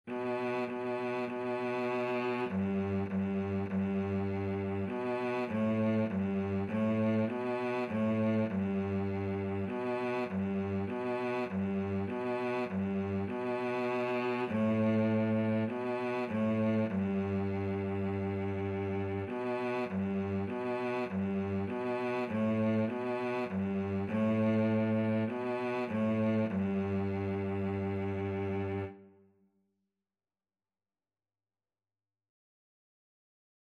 4/4 (View more 4/4 Music)
G3-B3
Beginners Level: Recommended for Beginners
Cello  (View more Beginners Cello Music)
Classical (View more Classical Cello Music)